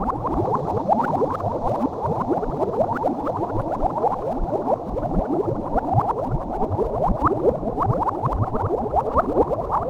potion_bubbles_brewing_loop_01.wav